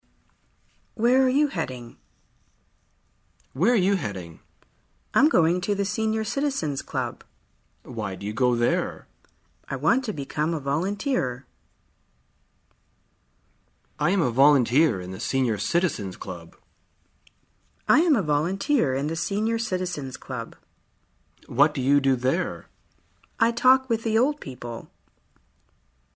在线英语听力室生活口语天天说 第15期:怎样谈论志愿者活动的听力文件下载,《生活口语天天说》栏目将日常生活中最常用到的口语句型进行收集和重点讲解。真人发音配字幕帮助英语爱好者们练习听力并进行口语跟读。